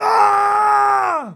Male_Death_Shout_02.wav